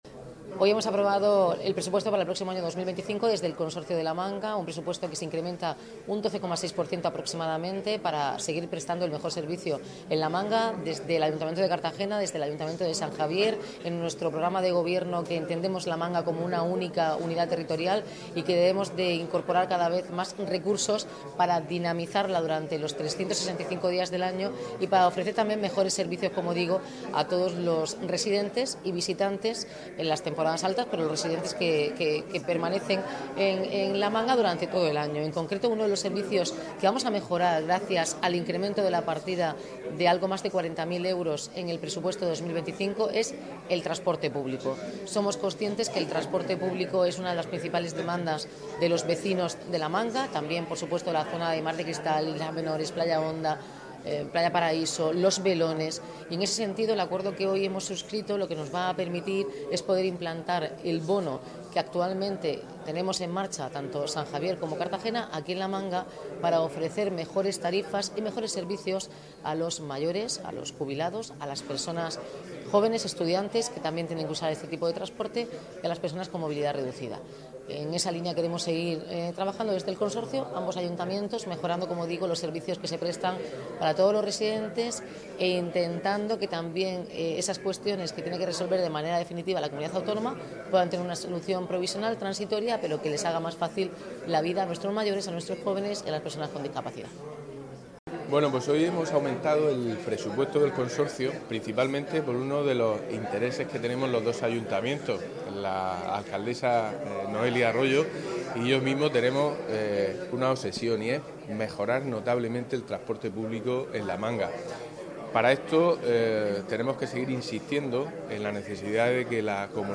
Enlace a Declaraciones de Noelia Arroyo y José Miguel Luengo.